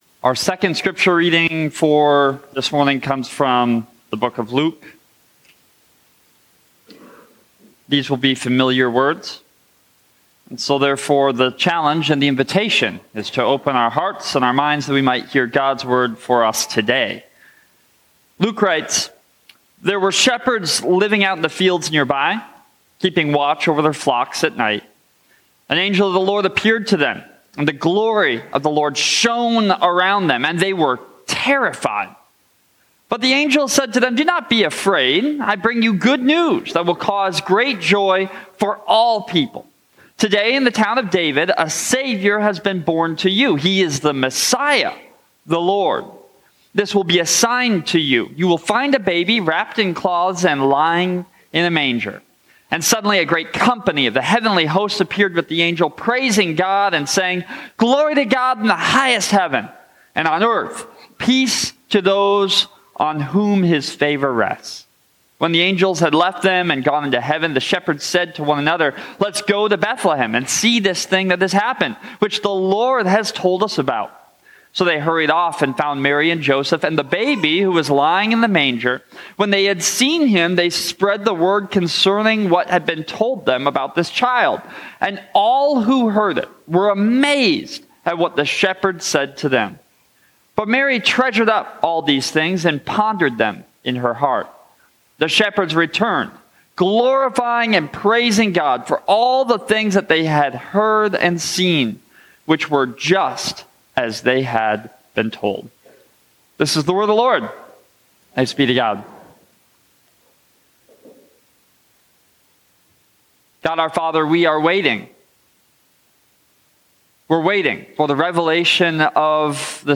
Sermons & Bulletins